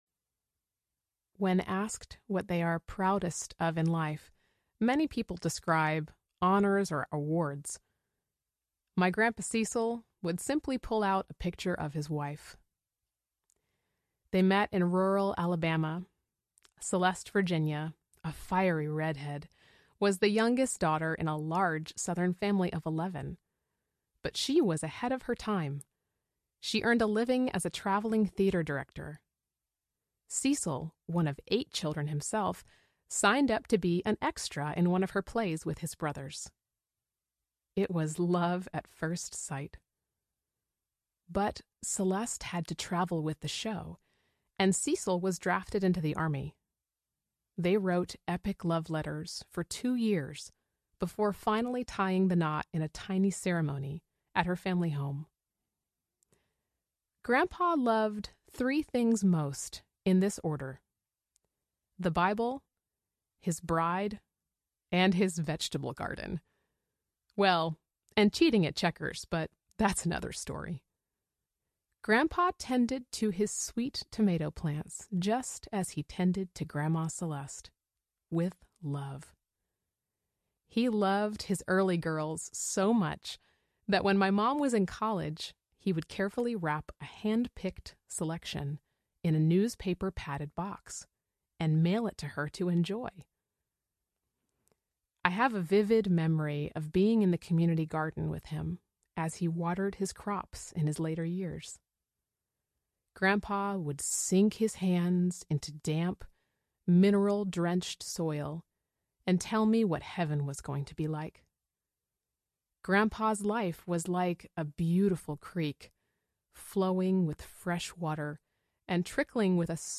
Make it Happen Audiobook